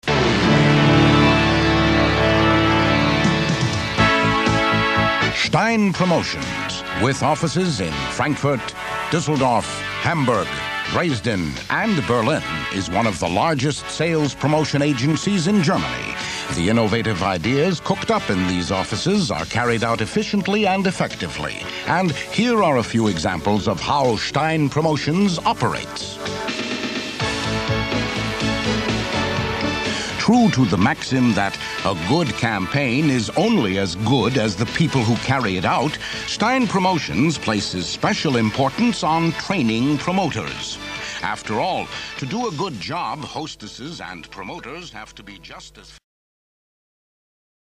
Amerikanischer Sprecher (transatlantisch) mit verschiedenen Stimmlagen und Akzente, TV Moderator, Schauspieler, Werbesprecher, Film-Vertonungen, Übersetzungen
mid-atlantic
Sprechprobe: Sonstiges (Muttersprache):